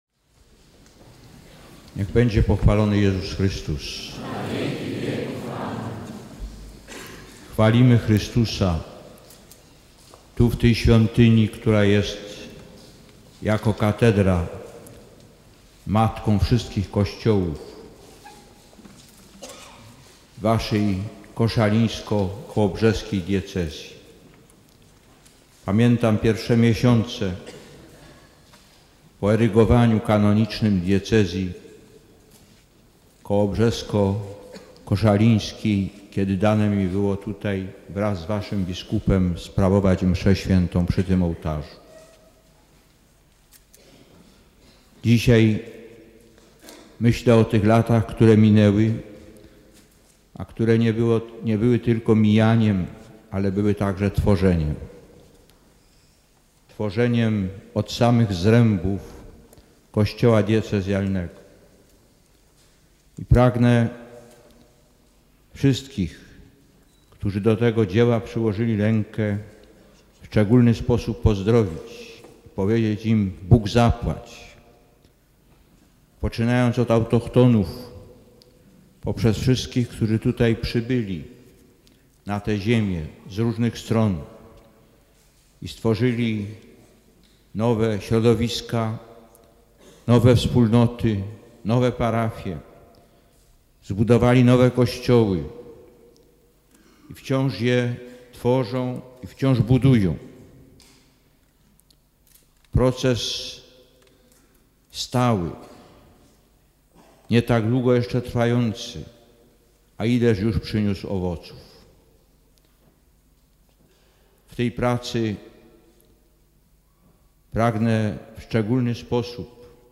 Tam o 20.30 przewodniczył nabożeństwu różańcowemu, transmitowanemu na cały świat przez Radio Watykańskie. Na zakończenie wygłosił krótkie rozważanie.
Nagranie pochodzi z zasobu archiwum Polskiego Radia Koszalin.